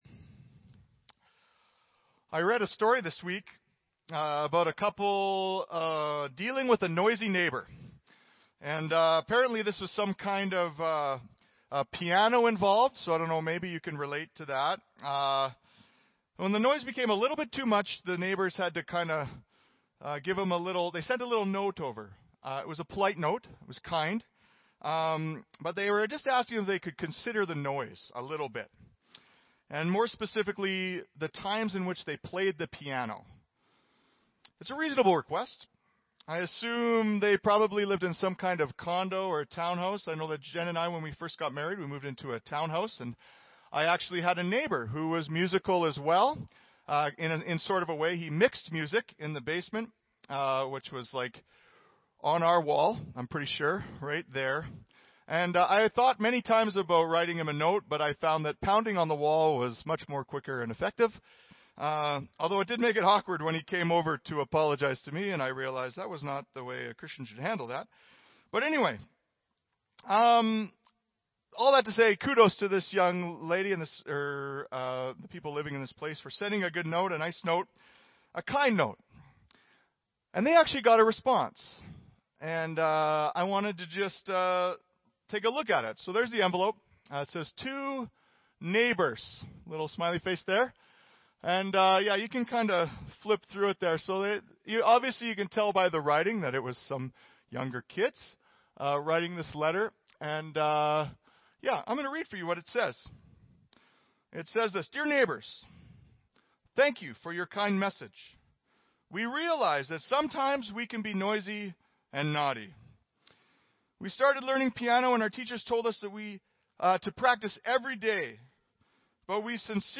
Audio Sermon Library